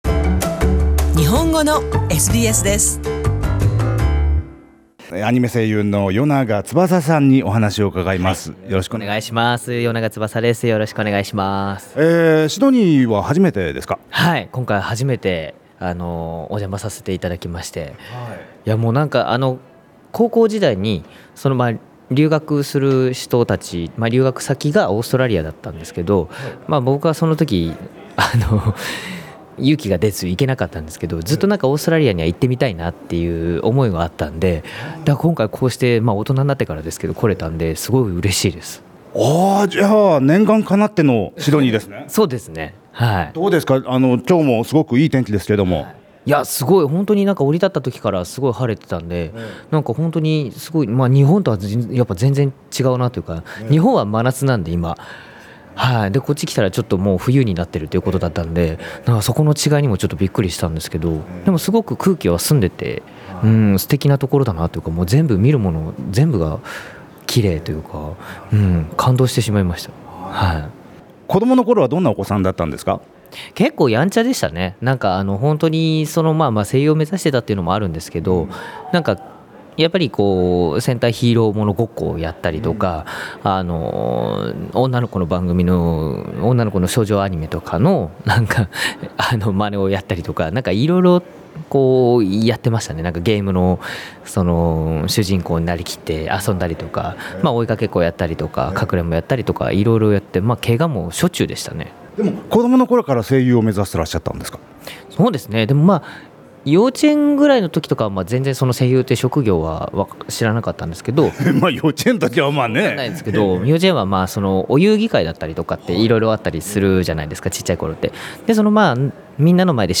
アニメ「おおきく振りかぶって」の主人公・三橋役など、数々のアニメ作品で人気を集める声優、代永翼さん。先月シドニーのダーリングハーバーで開かれたアニメと漫画の祭典 SMASH! に、特別ゲストとして招かれた代永さんに、声の仕事を通して自分の思いを伝える醍醐味などについてお聞きしました。